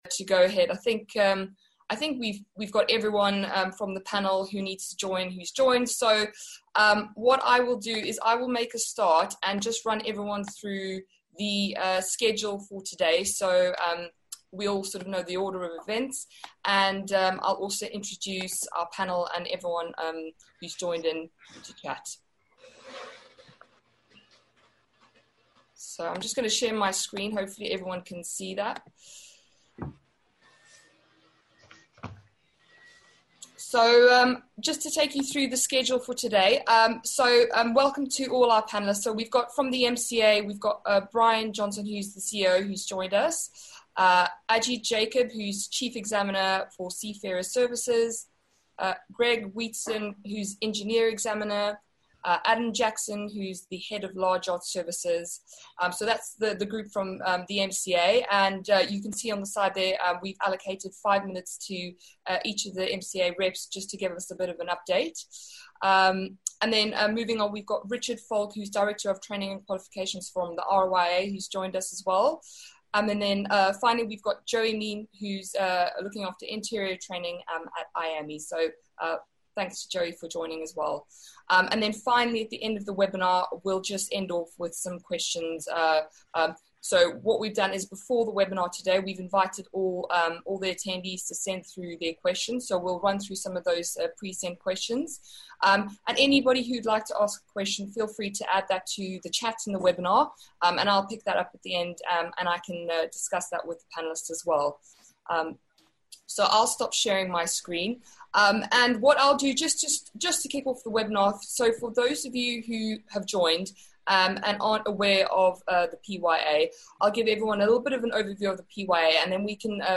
PYA Seachanges Webinar
We interview the industry's leading regulatory bodies who provide an update on training, certification and exams which have been affected by the COVID-19 pandemic.